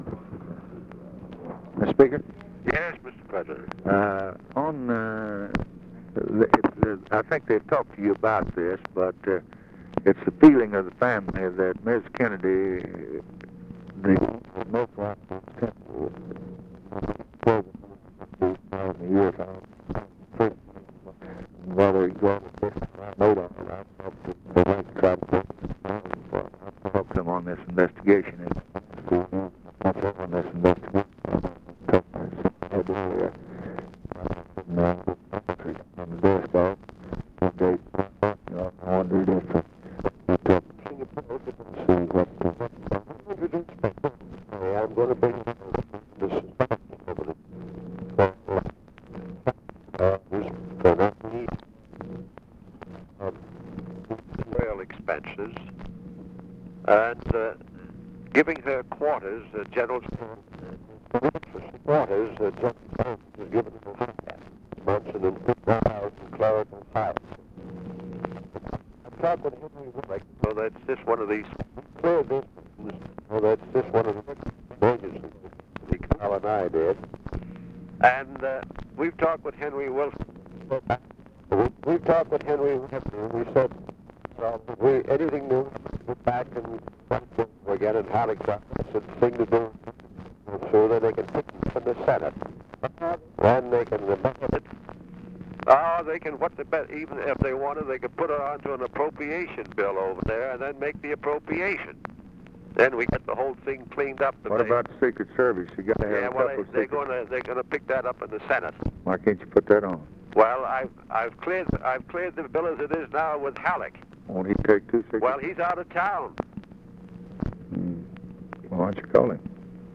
Conversation with JOHN MCCORMACK, November 29, 1963
Secret White House Tapes